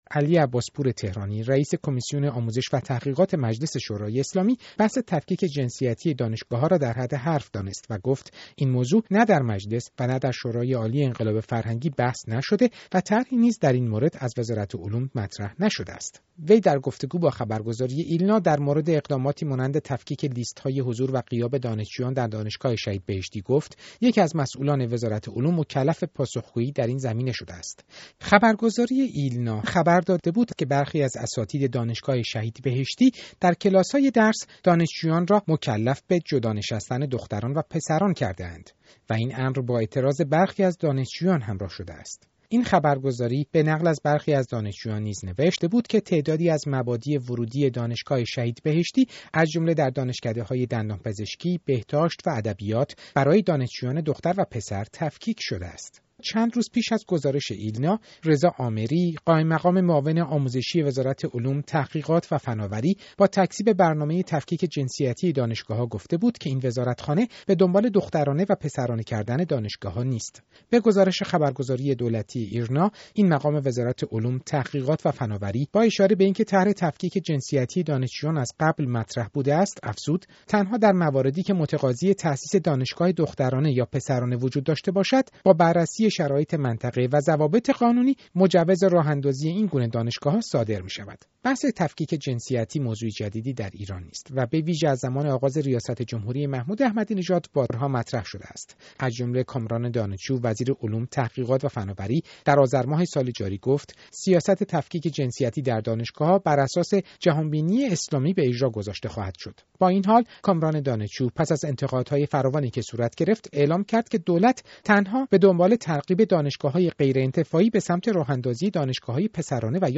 گزارش رادیویی در مورد تفکیک جنسیتی در دانشگاه‌ها